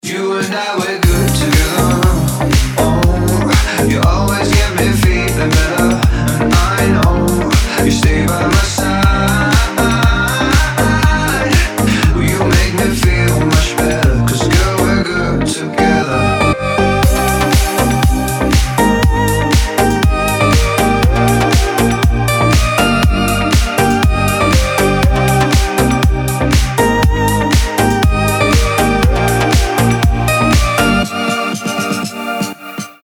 • Качество: 256, Stereo
поп
мужской вокал
deep house
dance
club
house
танцевальные
vocal